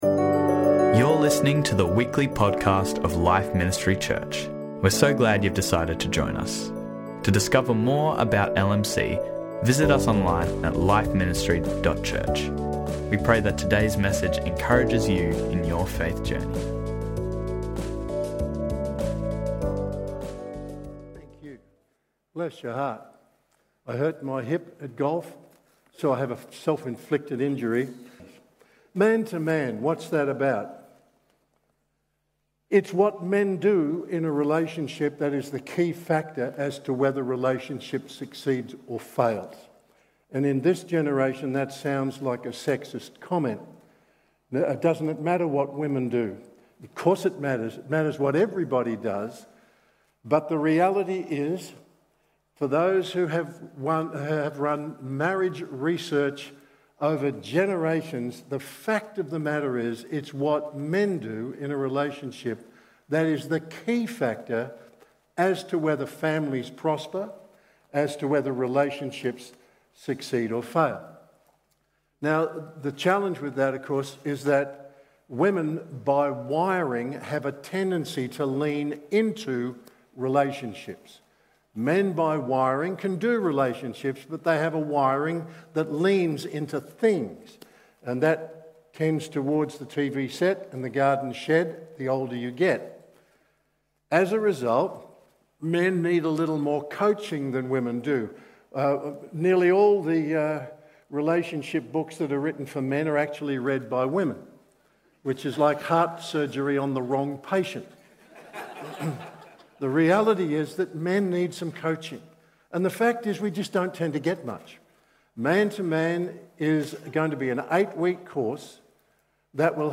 The Blessing And Curse of Payday | Life Ministry Church